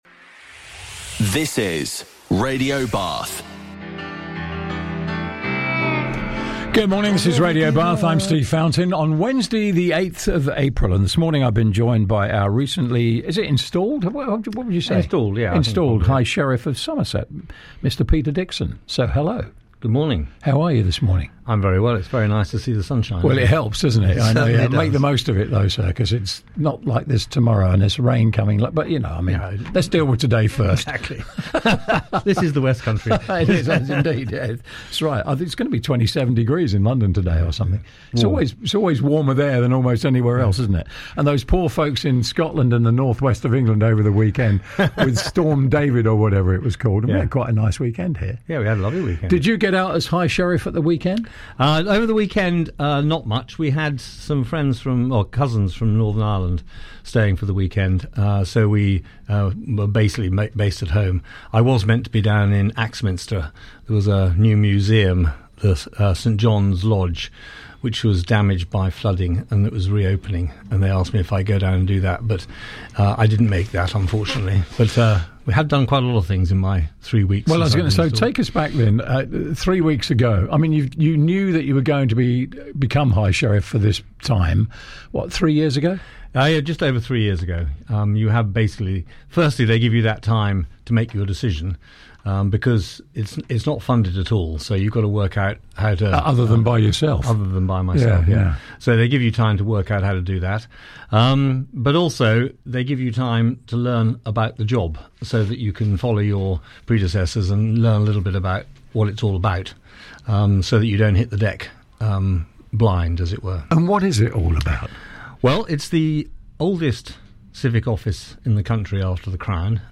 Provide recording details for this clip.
High-Sheriff-on-air-.mp3